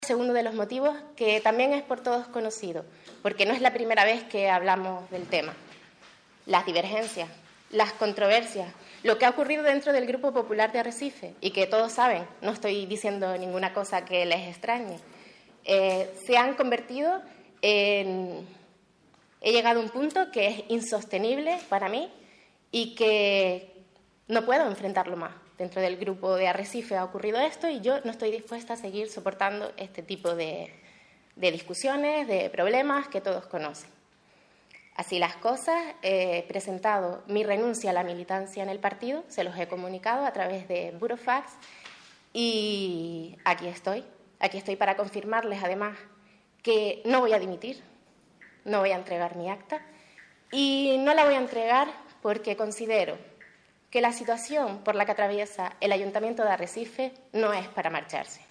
Así lo ha puesto de manifiesto la propia edil popular, que en rueda de prensa ofrecida a las 11 horas en el Archivo Municipal de Arrecife ha dejado claro que, a pesar de que la propia presidenta del PP en Lanzarote, Ástrid Pérez, le ha rogado que entregue su acta de concejal, no piensa hacerlo.